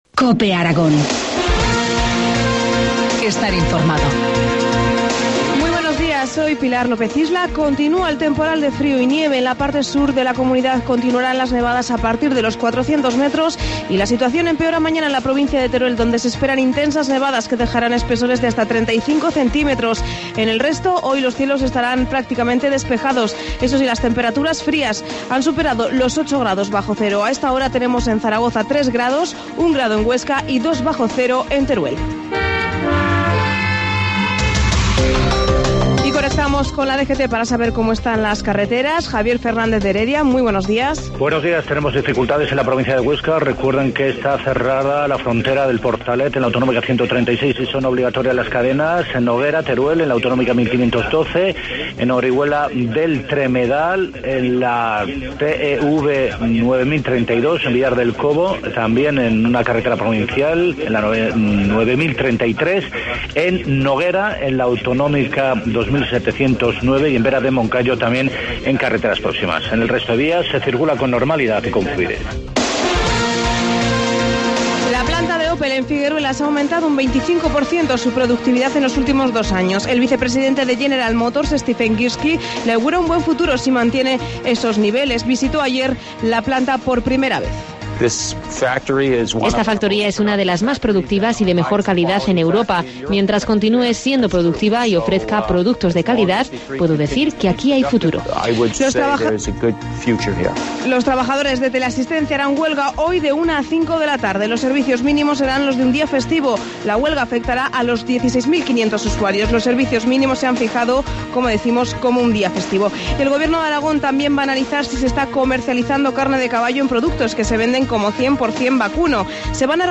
Informativo matinal, miércoles 27 de febrero, 7.25 horas